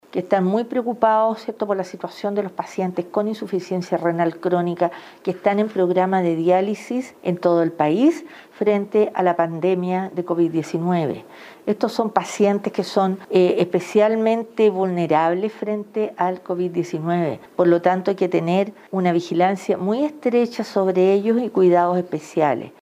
La autoridad regional reconoció una preocupación mayor con los pacientes que tienen dicha patología, ya que quieren una vigilancia estrecha durante la emergencia sanitaria.